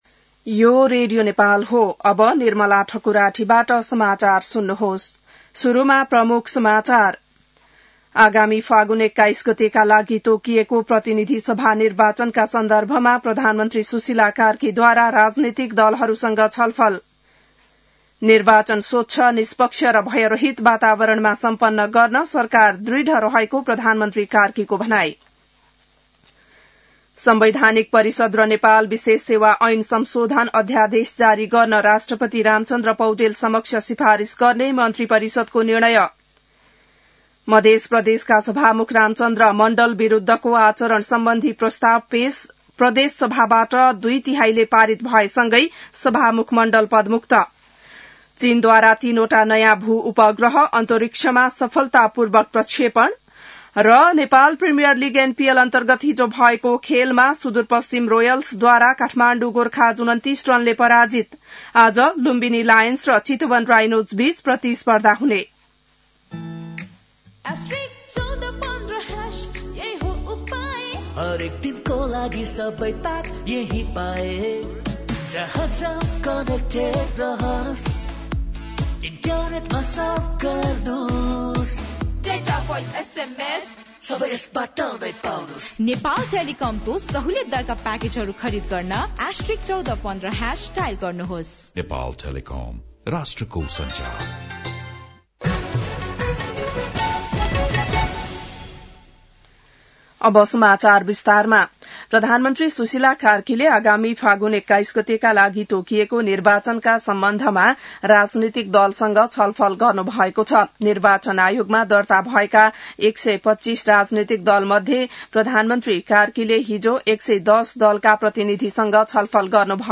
बिहान ७ बजेको नेपाली समाचार : ४ मंसिर , २०८२